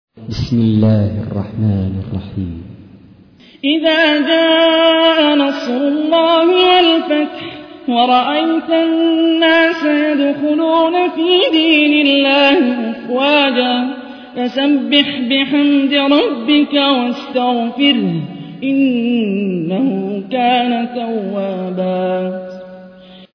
تحميل : 110. سورة النصر / القارئ هاني الرفاعي / القرآن الكريم / موقع يا حسين